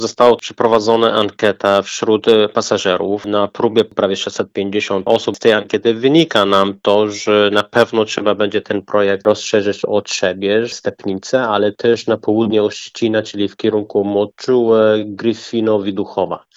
Jak dodaje starosta policki Shivan Fate w przyszłym roku planowane jest rozszerzenie przedsięwzięcia o kolejne miejscowości regionu: